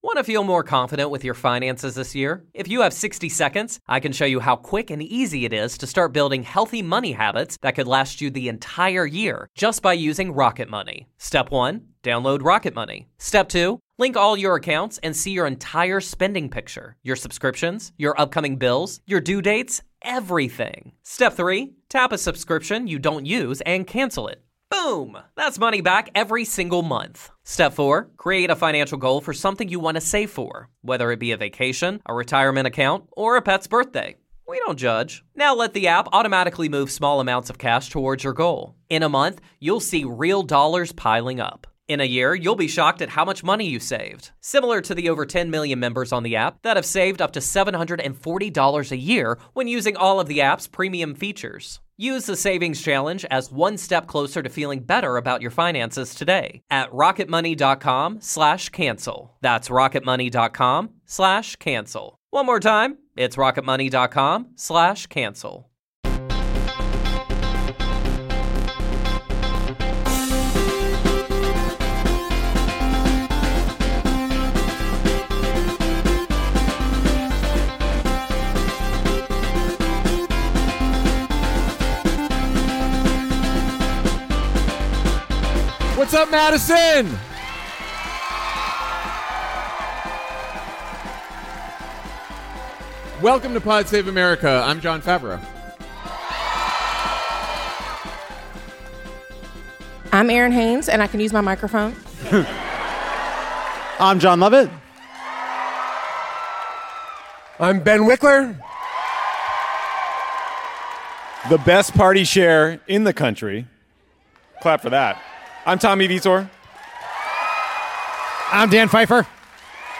Live in Madison